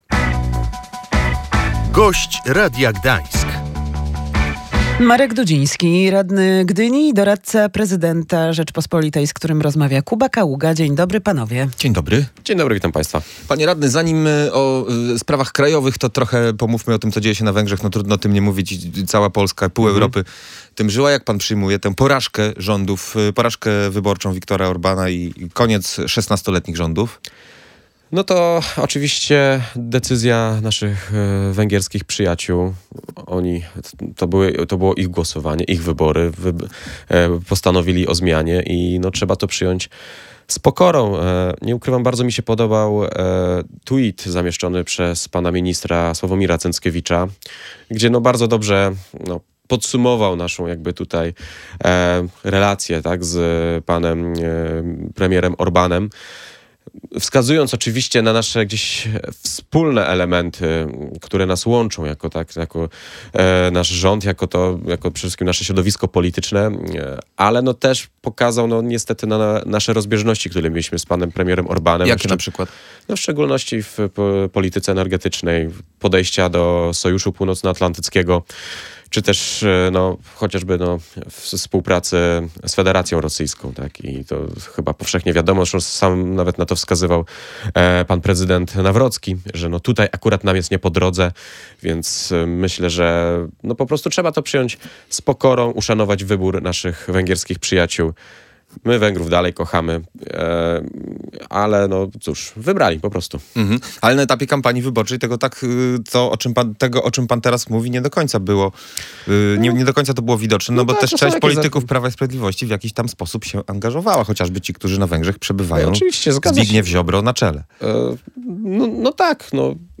Nie rozumiem wielkiej radości samorządowców, którzy ostatnio tak się cieszyli tym, że ustawa metropolitalna wyszła z rządu – mówił w Radiu Gdańsk gdyński radny i doradca prezydenta RP Marek Dudziński.